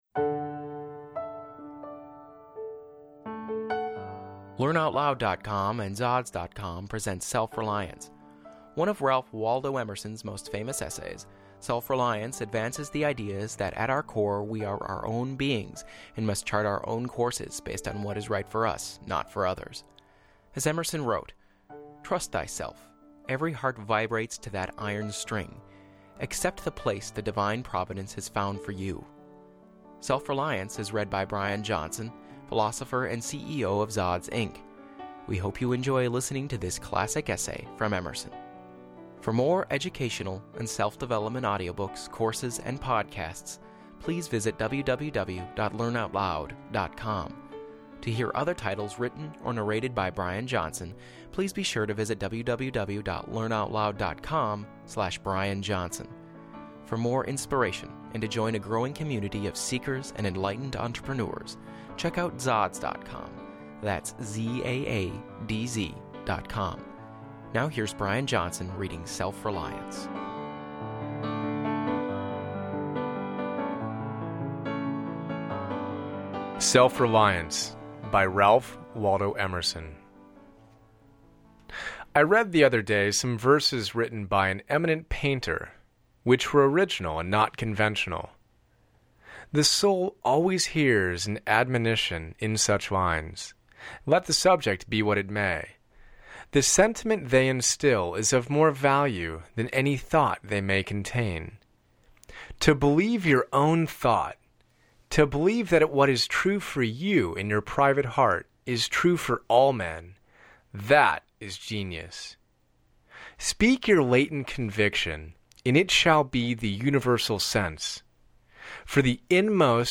Self Reliance by Ralph Waldo Emerson on Free Audio Book Download